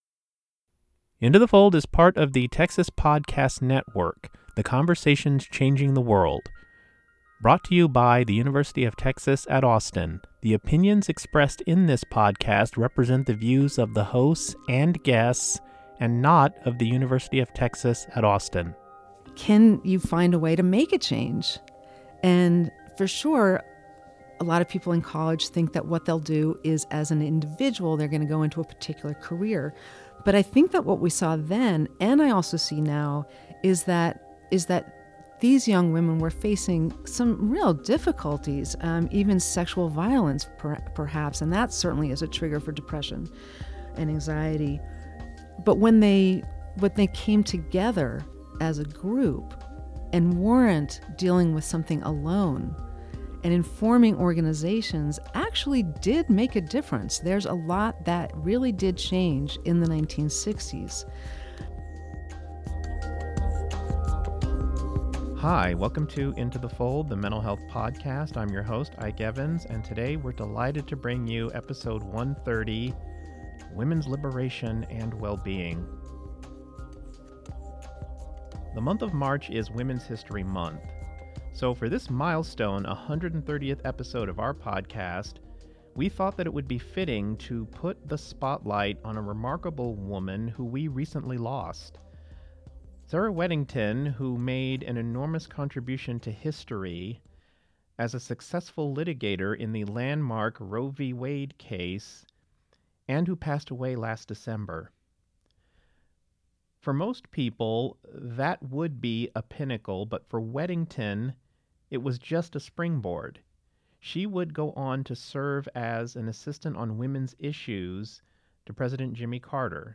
For this milestone 130th episode of our podcast, we put the spotlight on a remarkable woman who we recently lost: Sarah Weddington, who made an enormous contribution to history as a successful litigator in the landmark Roe V. Wade case, and who passed away last December. She appeared in a 1970's episode of The Human Condition, the radio series produced by the Hogg Foundation from 1971 to 1983. We have reproduced this episode for a wide-ranging discussion of Weddington's life and legacy.